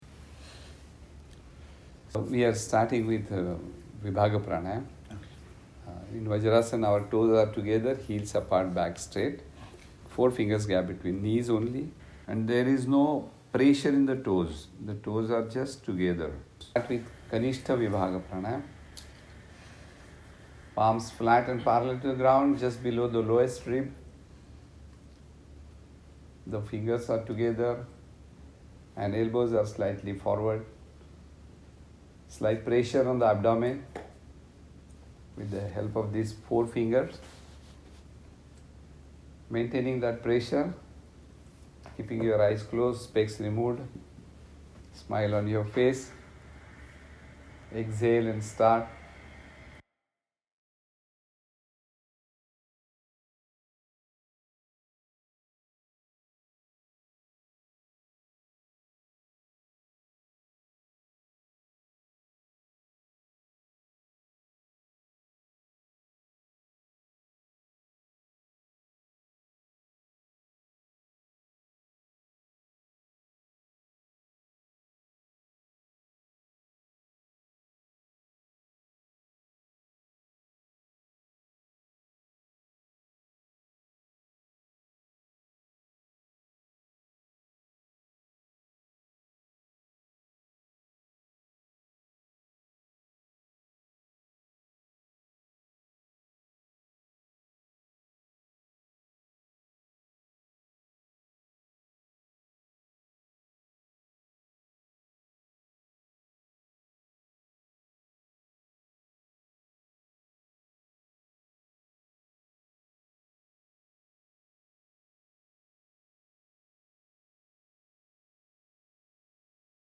Welcome SSY Sadhaka Pranayama Instructions